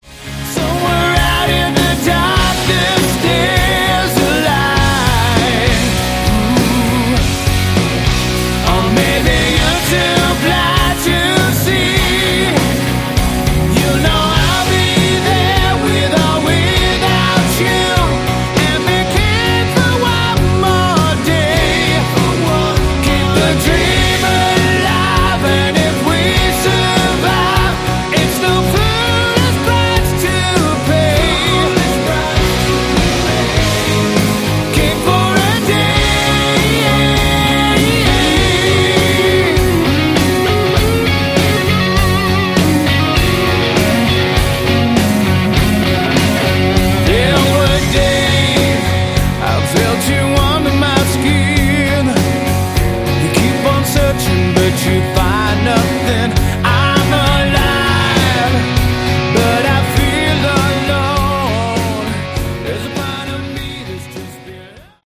Category: Hard Rock
vocals
guitars
bass
drums